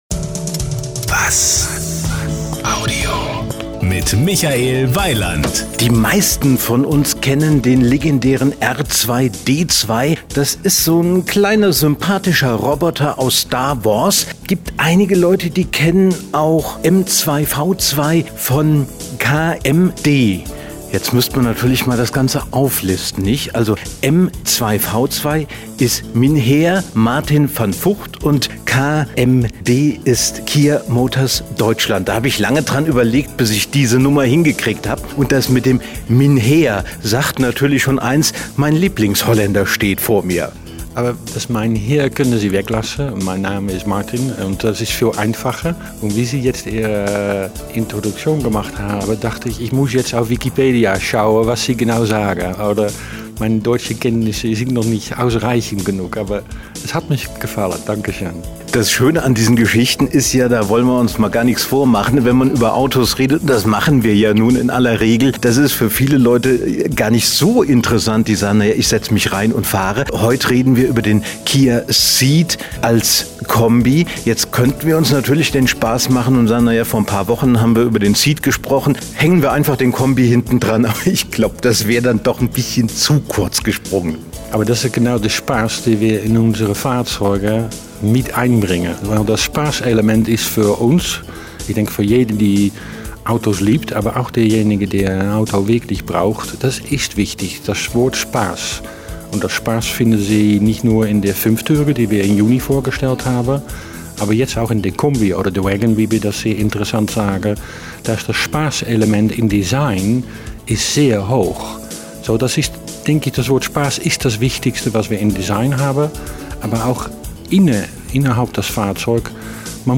Nun folgt das Interview zum dazugehörigen Kombi, der auf den Namen "Sportwagon" hört!